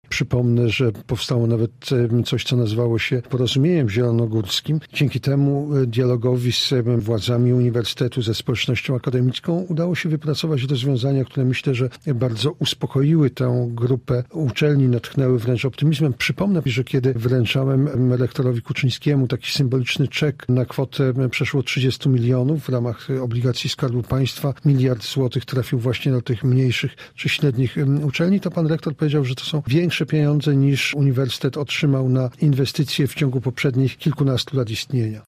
Nie jest tajemnicą, że część środowiska Uniwersytetu Zielonogórskiego była zaniepokojona zmianami, które weszły w życie rok temu – mówił Jarosław Gowin: